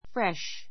fresh 小 A2 fréʃ ふ レ シュ 形容詞 ❶ 新しい , 新鮮 しんせん な , 爽 さわ やかな fresh air fresh air 新鮮な[爽やかな] 空気 fresh leaves fresh leaves 若葉 feel fresh feel fresh 爽やかに感じる[で気持ちがよい] The grass was fresh with dew.